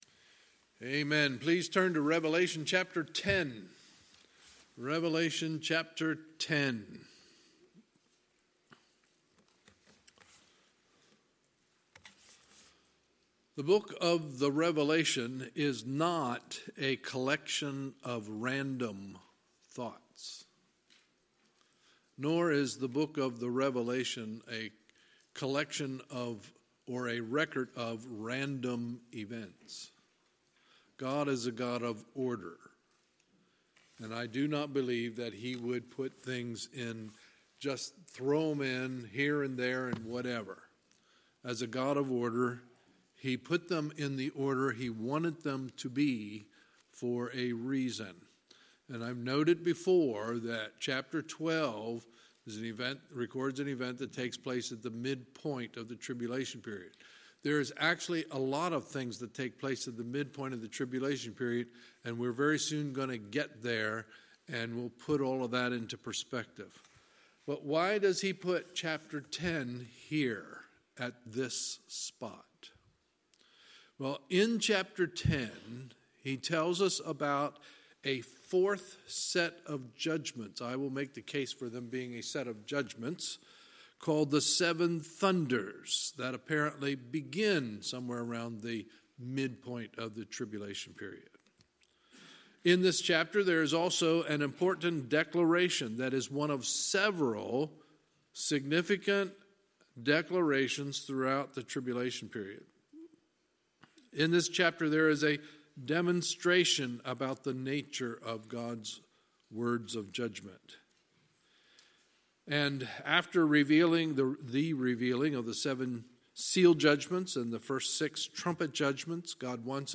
Sunday, February 17, 2019 – Sunday Evening Service
Sermons